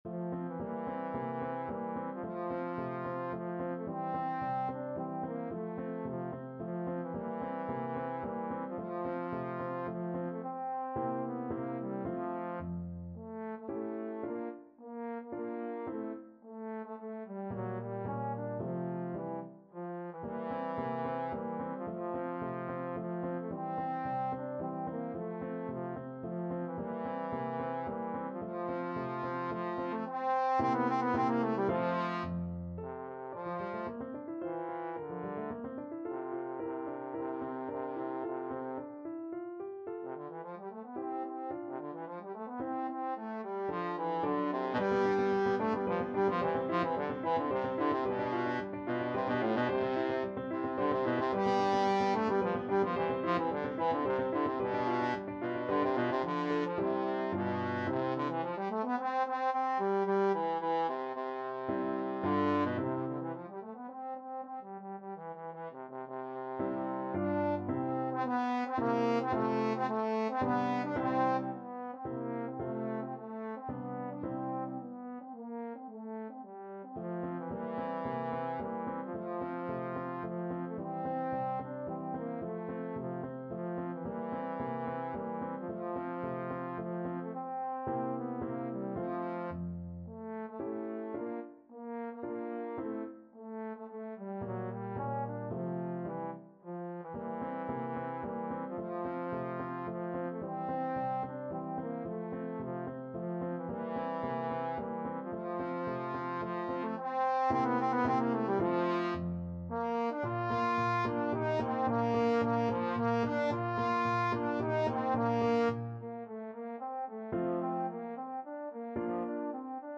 Trombone
F major (Sounding Pitch) (View more F major Music for Trombone )
II: Tempo di Menuetto =110
3/4 (View more 3/4 Music)
Classical (View more Classical Trombone Music)